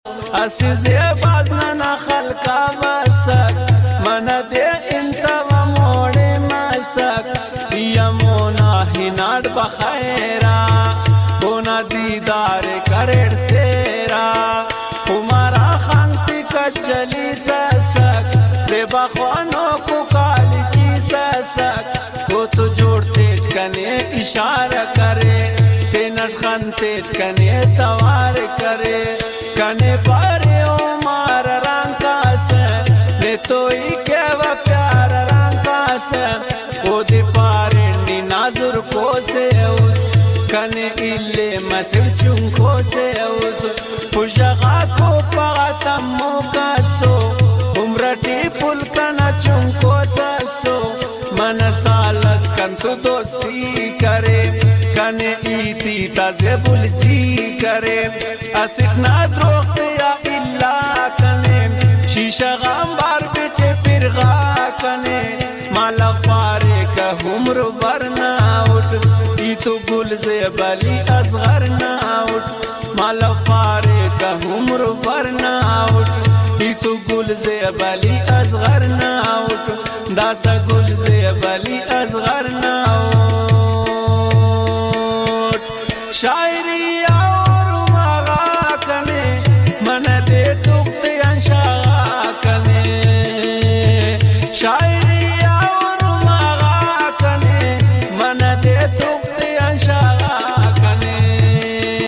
Brahvi Trending song